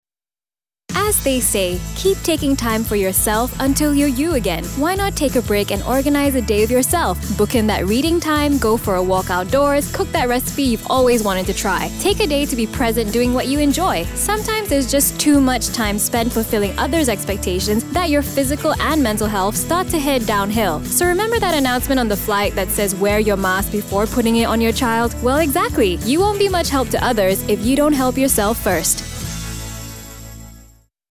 Female
Character Announcer Voice Prompt
Energetic Young Corporate High-pitched Sexy Conversational
PSA - Help Yourself First - Conversational/ Young/ Clear/ Inspiring